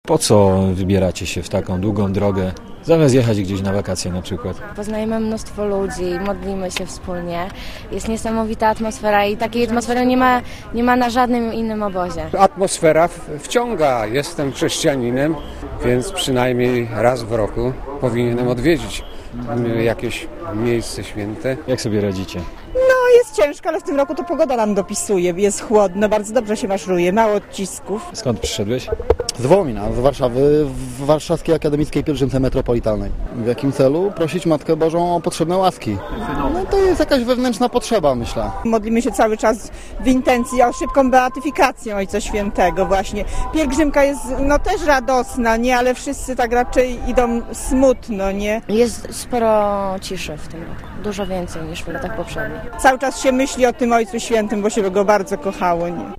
180 tys. wiernych wzięło udział w uroczystej mszy z okazji Wniebowzięcia Najświętszej Maryi Panny na jasnogórskich błoniach w Częstochowie.
Relacja
jasna_gora_-pielgrzymi.mp3